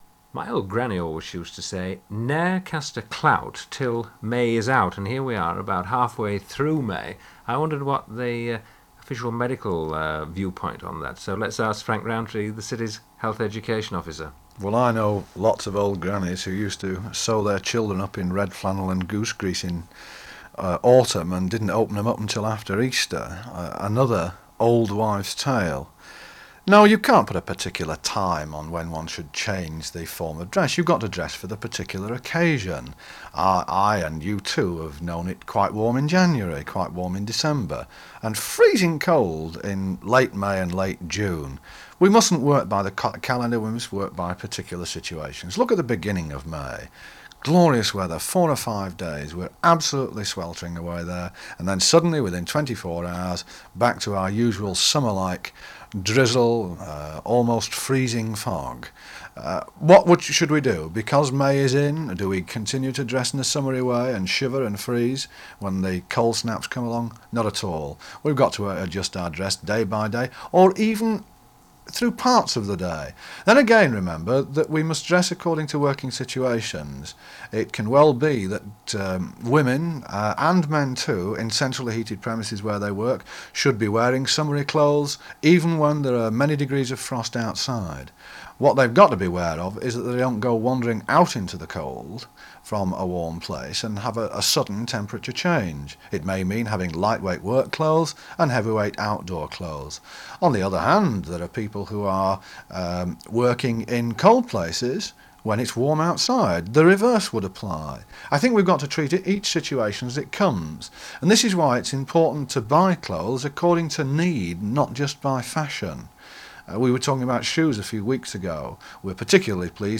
Sheffield : BBC Radio Sheffield, 1972.